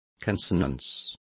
Complete with pronunciation of the translation of consonance.